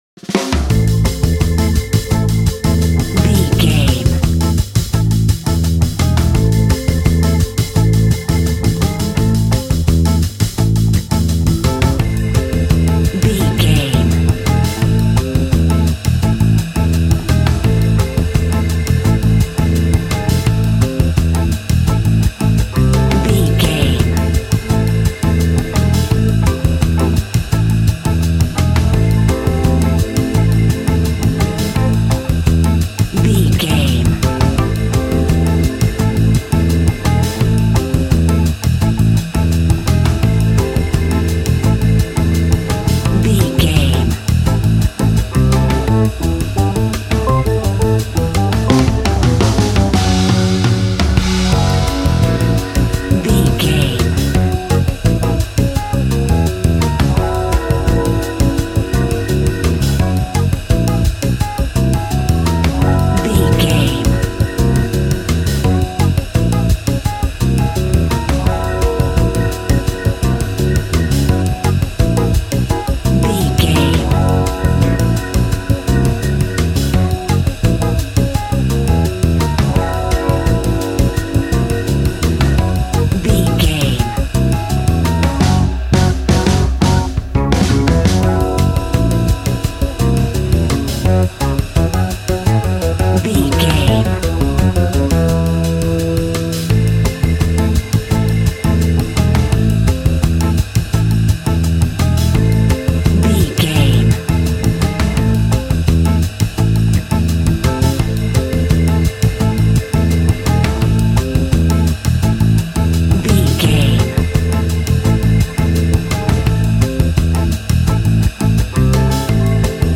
Aeolian/Minor
relaxed
smooth
synthesiser
drums
80s